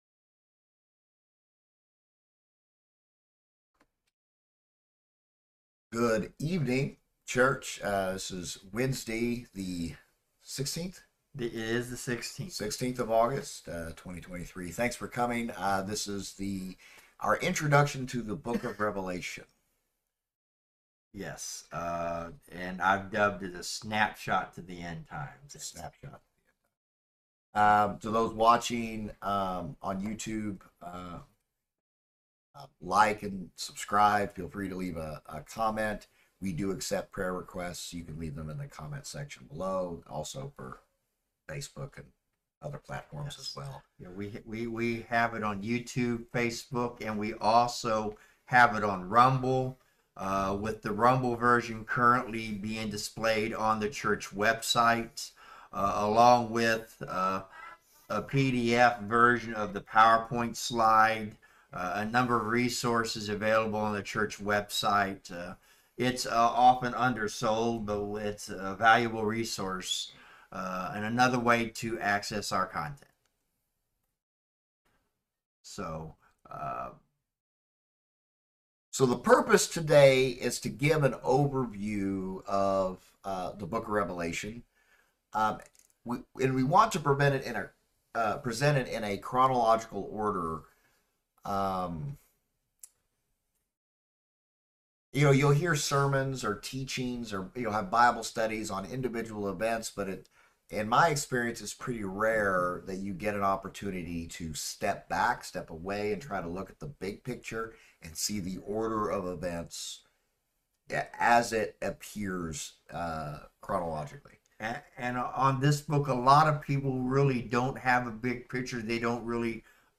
In tonight’s discussion is a broad outline of the chronological narrative of Revelation.
Service Type: Wednesday Word Bible Study